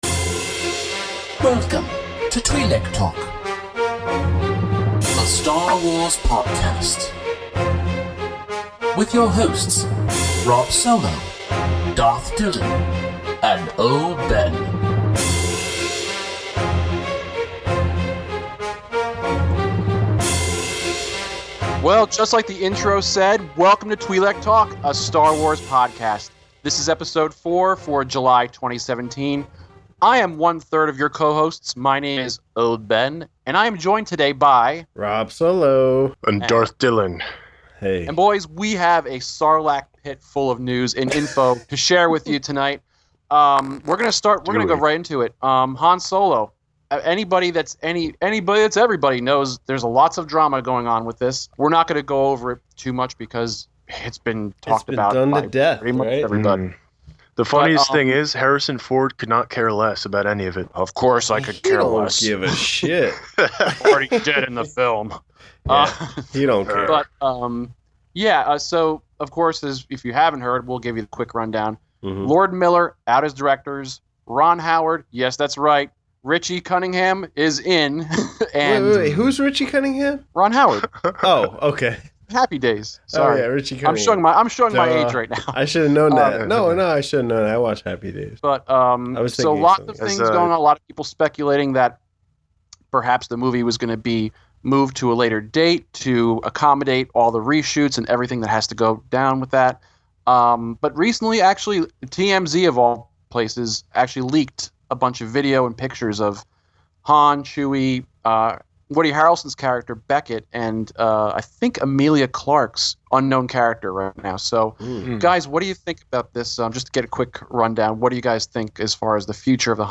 My only comment/suggestion would be to upgrade your audio quality significantly.